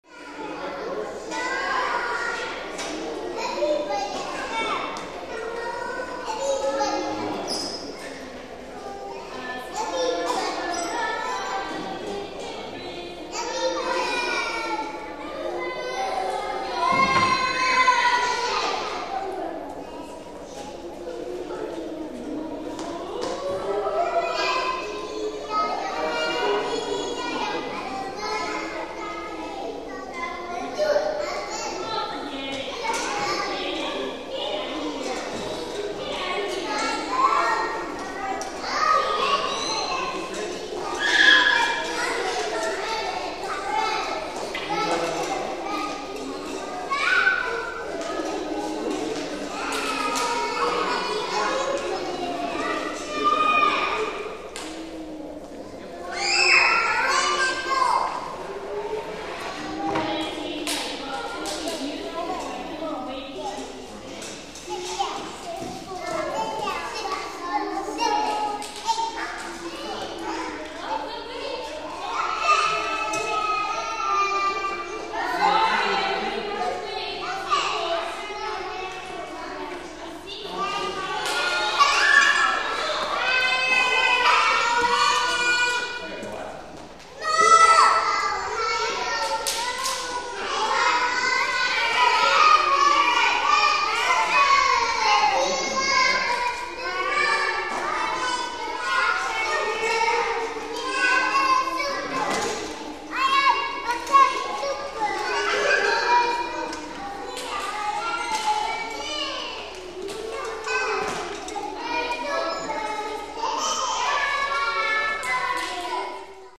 Звуки школьной перемены
Школьная перемена звуки игры шум гам